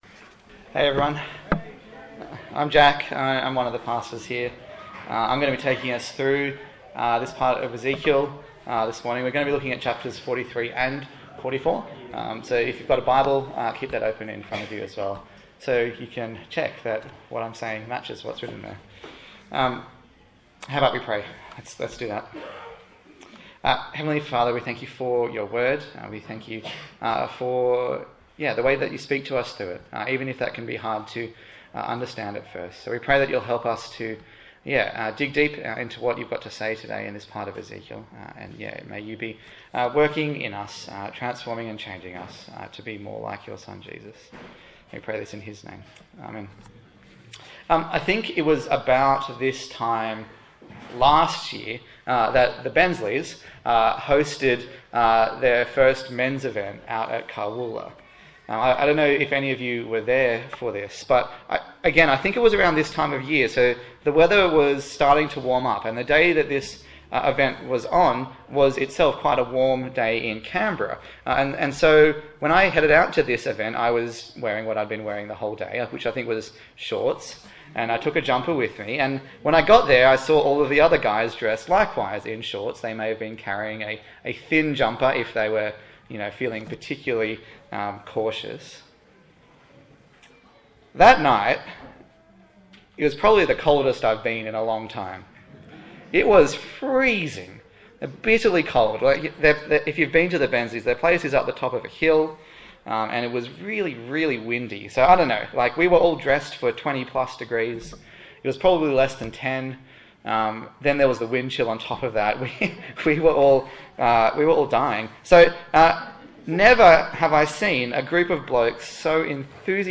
A sermon in the series on Ezekiel
Service Type: Sunday Morning